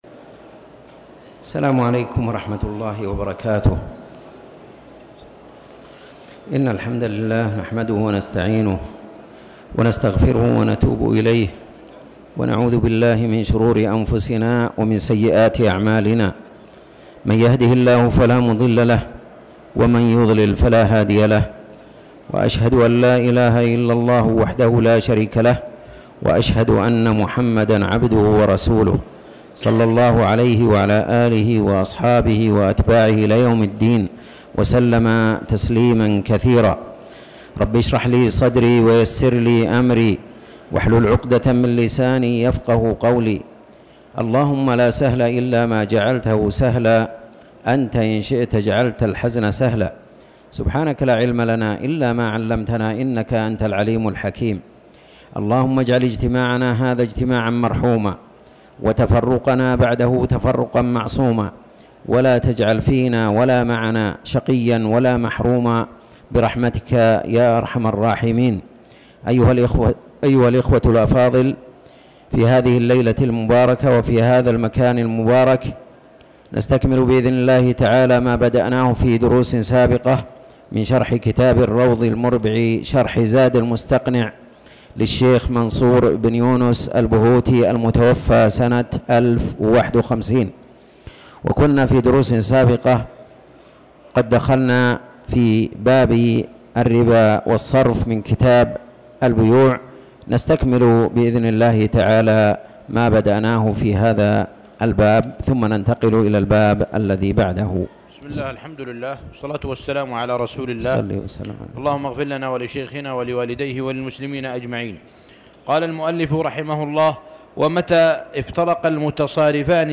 تاريخ النشر ١٤ جمادى الآخرة ١٤٤٠ هـ المكان: المسجد الحرام الشيخ